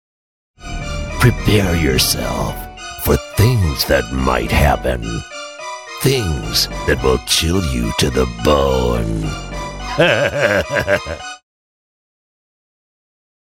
Deep, Mature, Friendly
Halloween - Deep Scary